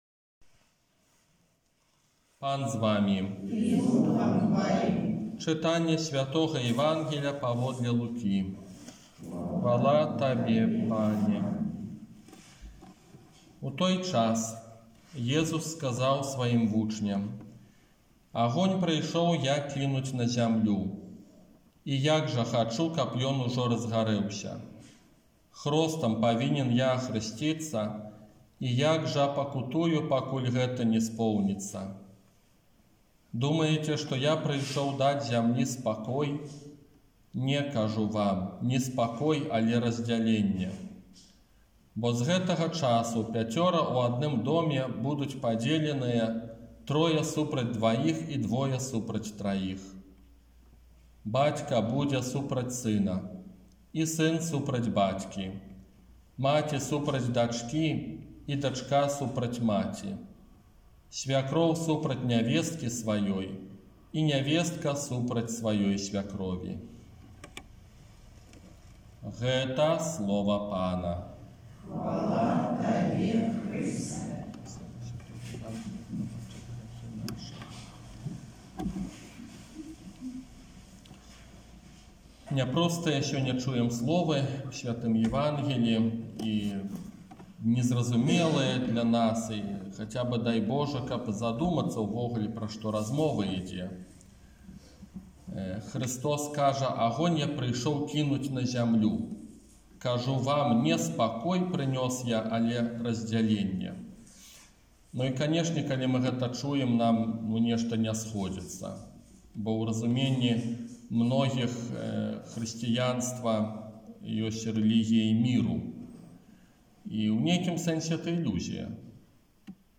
ОРША - ПАРАФІЯ СВЯТОГА ЯЗЭПА
Казанне на дваццаць другую звычайную нядзелю